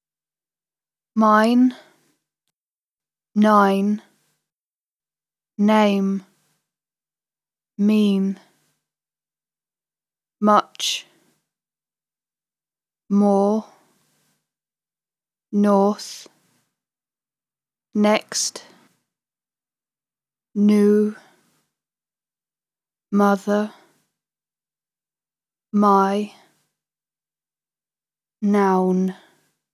Übung zur Förderung der phonologischen Bewusstheit (MP3 – klicken Sie auf den Abspiel-Button).
Alle Texte und Wortlisten wurden von englischen Muttersprachlern eingesprochen und können so besonders erfolgreich in das Training eingebunden werden.